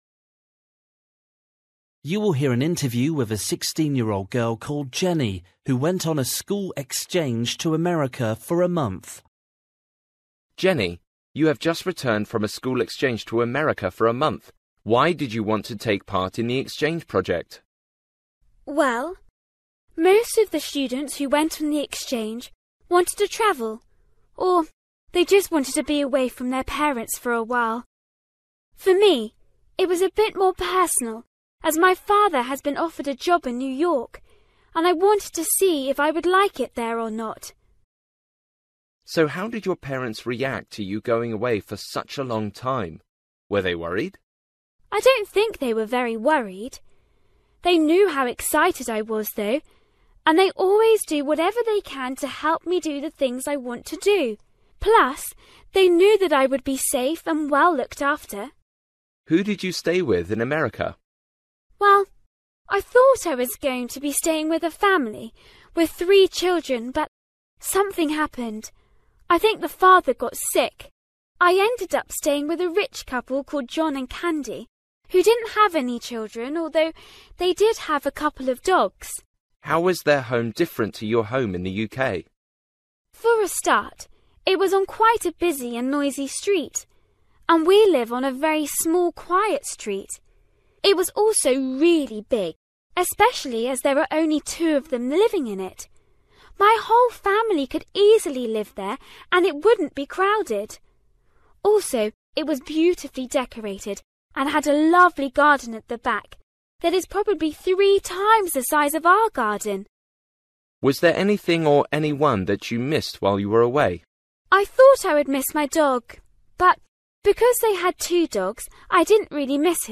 You will hear an interview with a 16-year-old girl